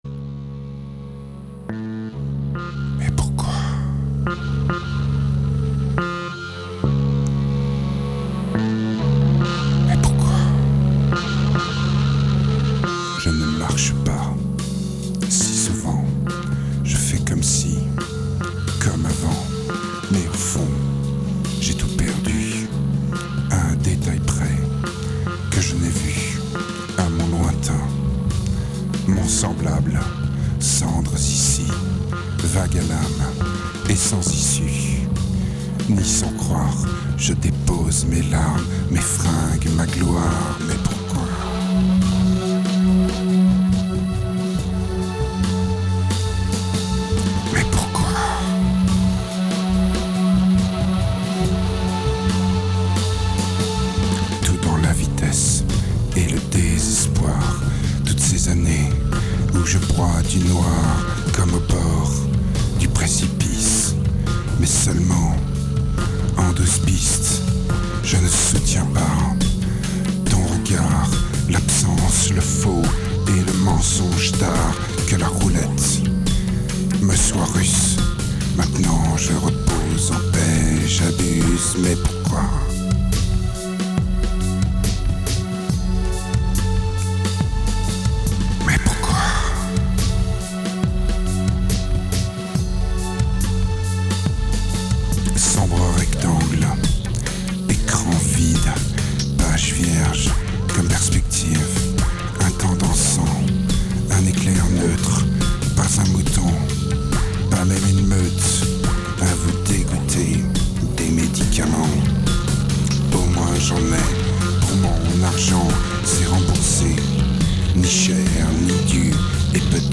2176📈 - -38%🤔 - 140BPM🔊 - 2009-01-11📅 - -301🌟